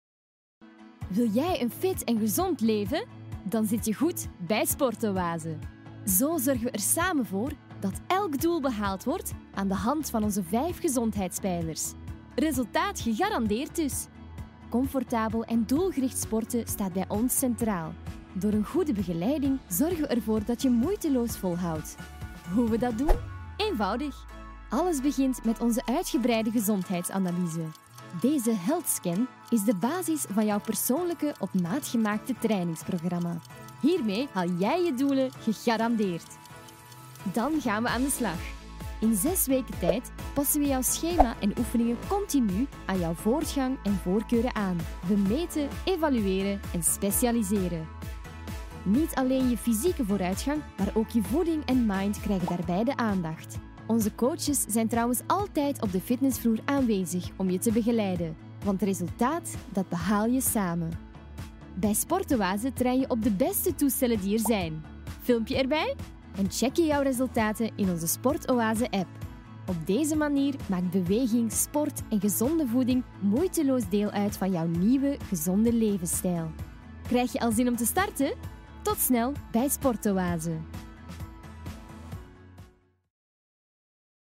Commercial, Young, Natural, Versatile, Friendly
Explainer